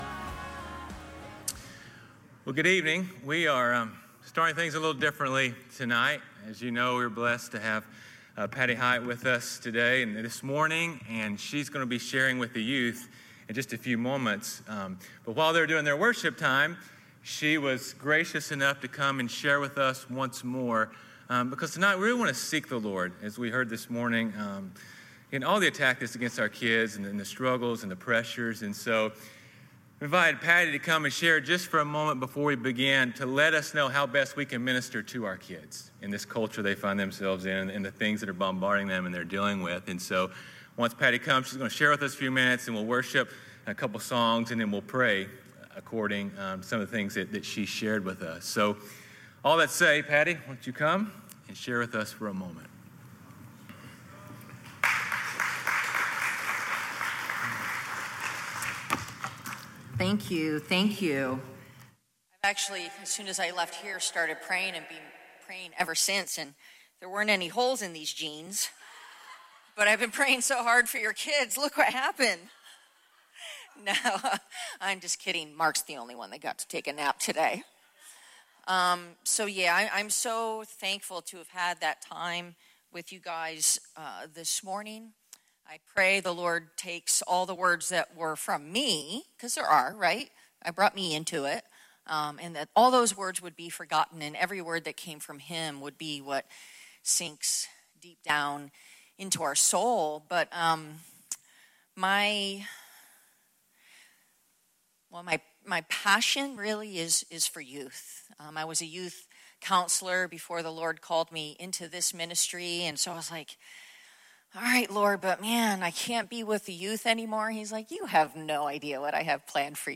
Calvary Knoxville Sunday PM Live!